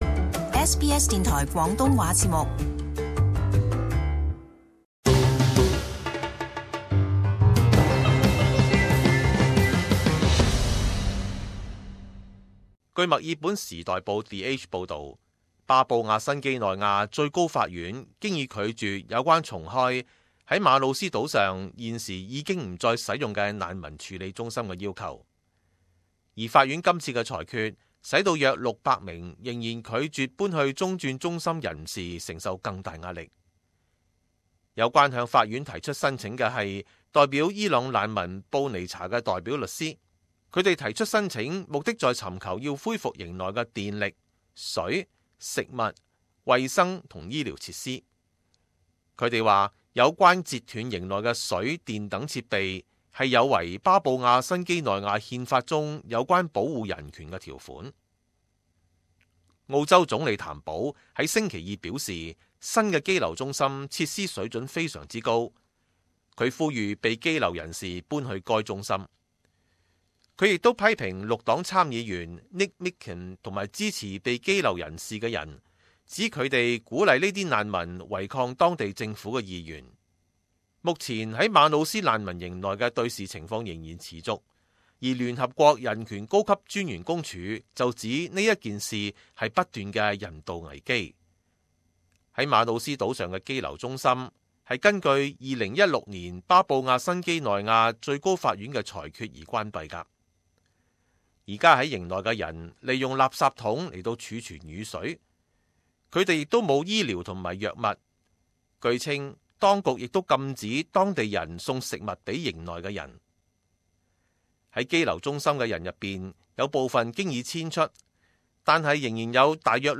【時事報導】 巴布亞新畿內亞高院拒絕重開馬努斯羈留中心